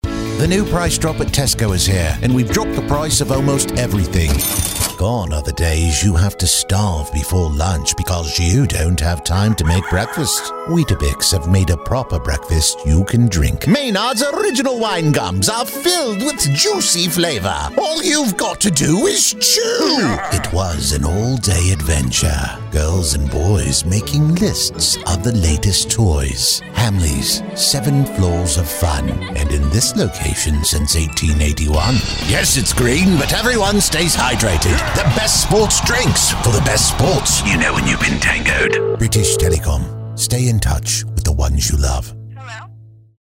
Commercial - UK
All vocals are recorded in a professional studio with state of the art equipment including Sennheiser MKH-416, Avalon 737 preamp, and Adobe Audition DAW.